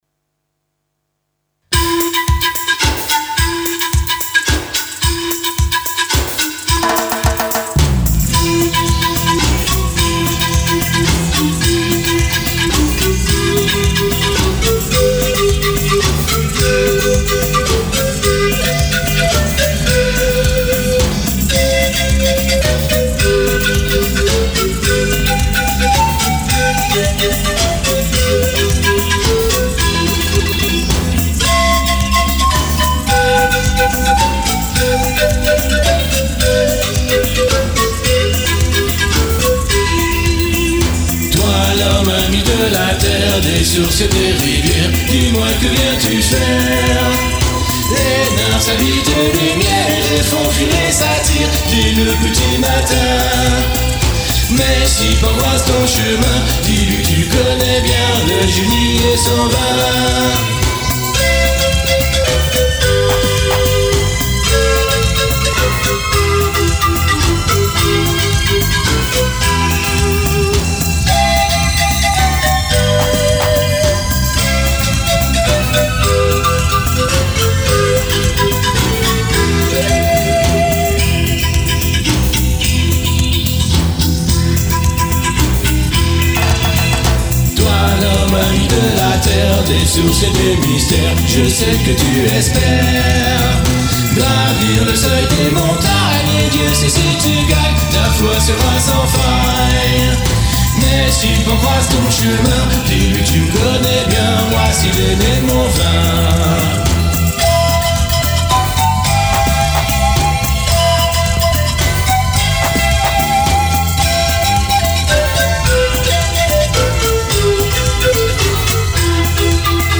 Le single a été remastérisé en aout 2025.